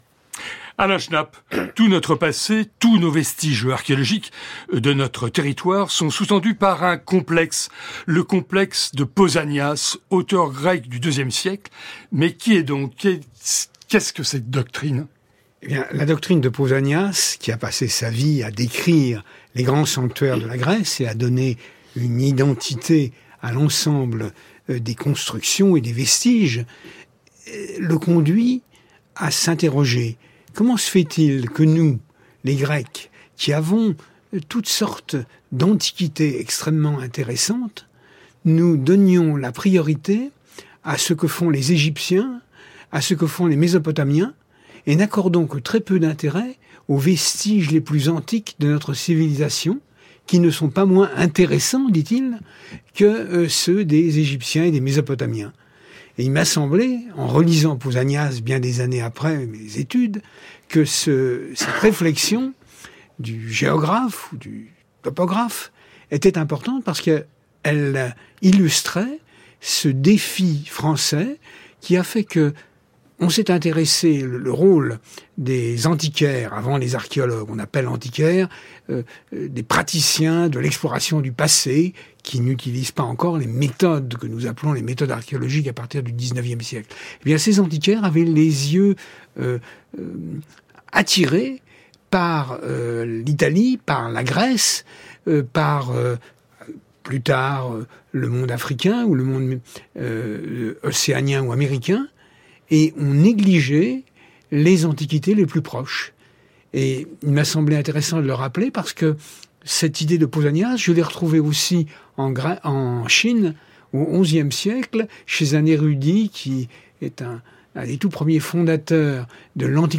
À l'écoute de l'extrait de l'émission de radio ci-dessous on pourra comprendre que le complexe de Pausanias est généralisé.